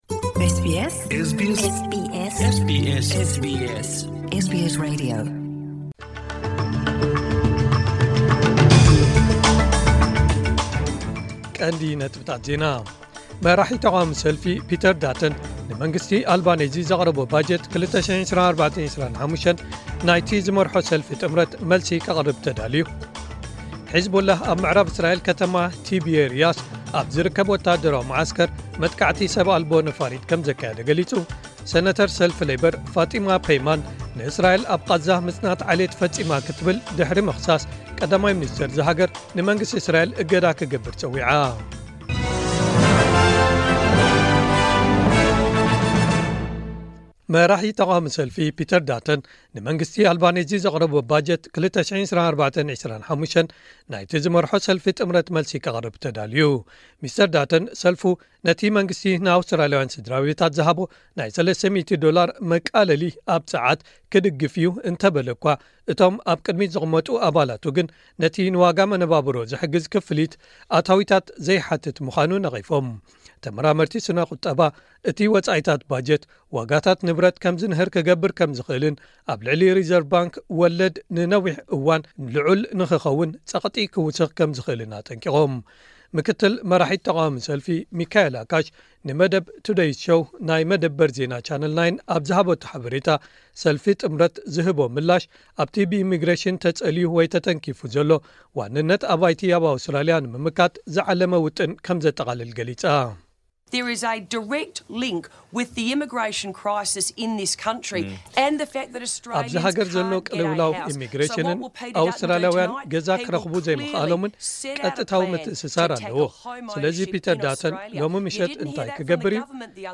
ዜናታት ኤስ ቢ ኤስ ትግርኛ (16 ግንቦት 2024)